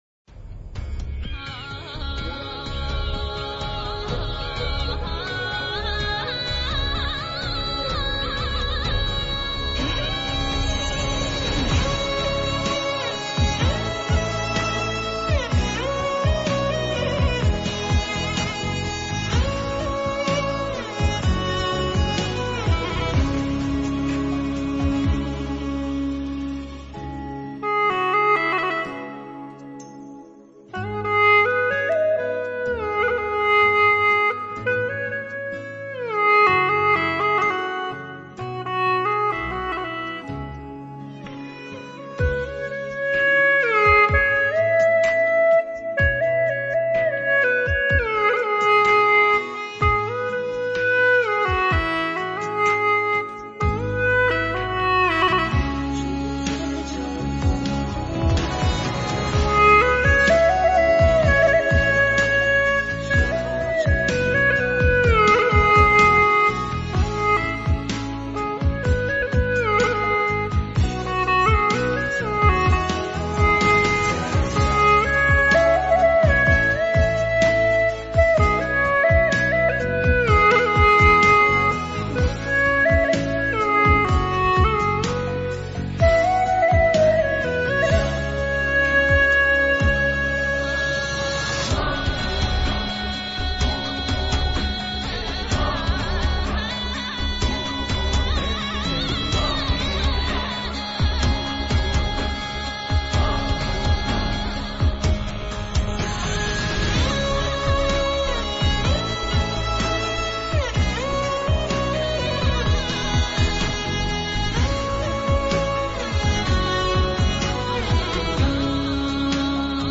调式 : E 曲类 : 流行